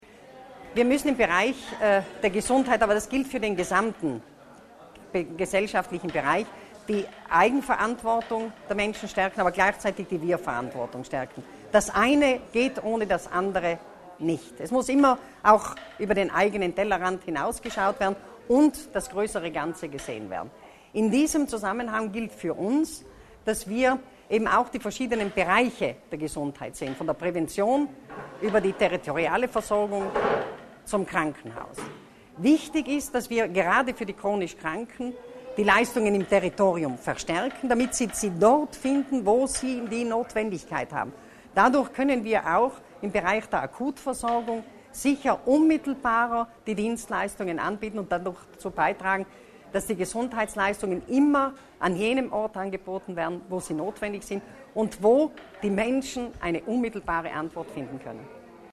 Die Landesrätin für Gesundheit, Sport, Soziales und Arbeit, Martha Stocker, hat die Pressekonferenz am heutigen Freitag für eine Standortbestimmung, eine Zwischenbilanz und eine Vorschau zur Halbzeit der Amtsperiode 2013-2018 genutzt. In der Sozialgenossenschaft Akrat am Bozner Matteottiplatz legte die Landesrätin dar, was sie bisher erfolgreich auf den Weg gebracht hat und welche Herausforderungen in den nächsten Jahren anstehen.